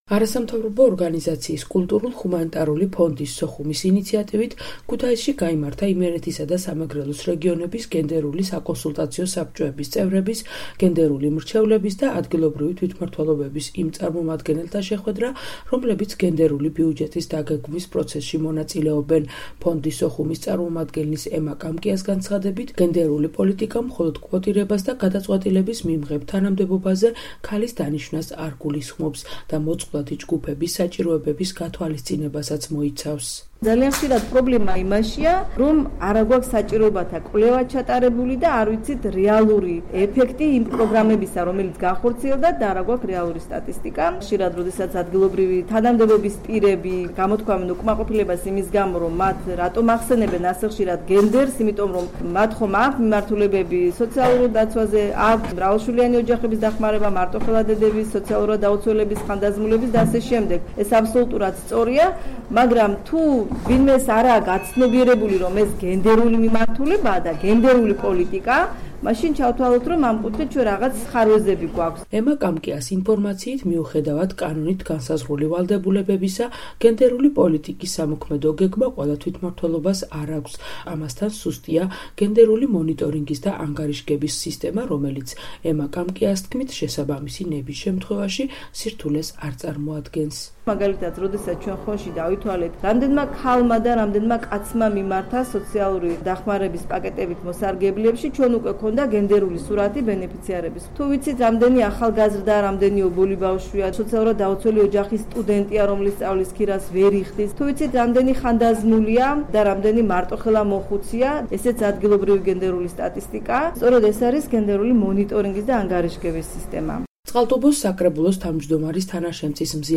შეხვედრა ქუთაისში